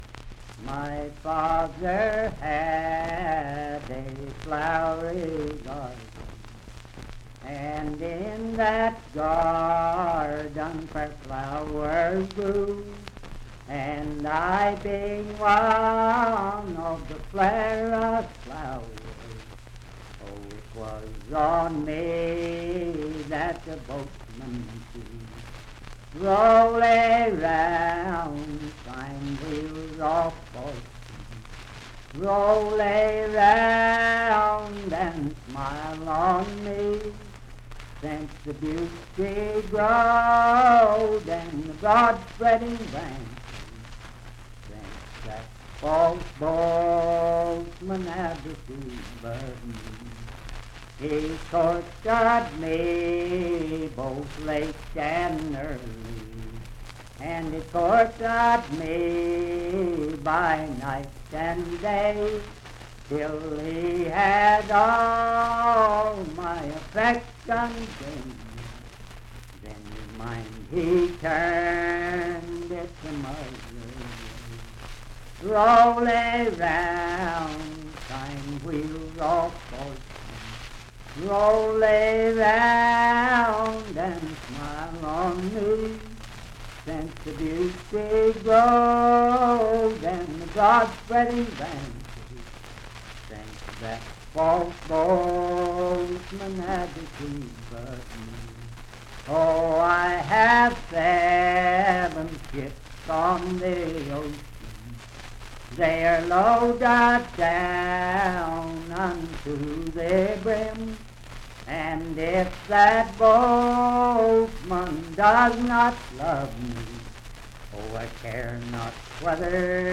Unaccompanied vocal music and folktales
Verse-refrain 3(4) & R(4).
Voice (sung)